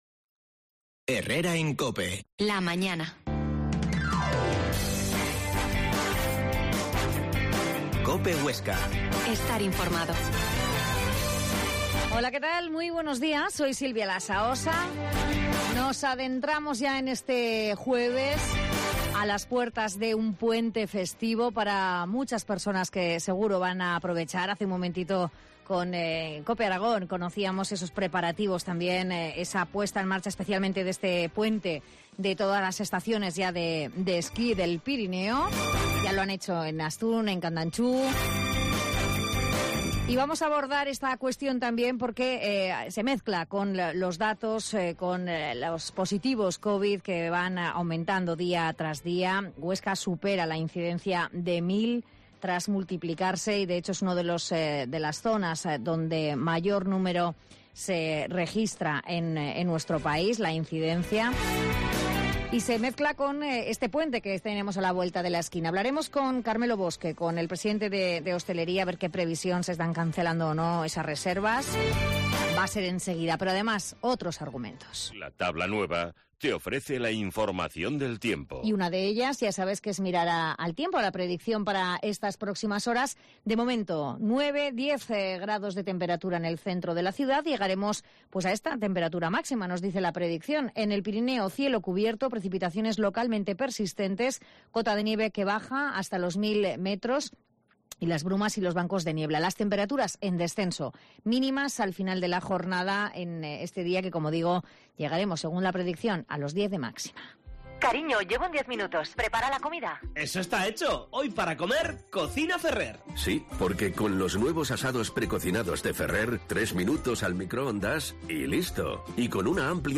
La Mañana en COPE Huesca - Magazine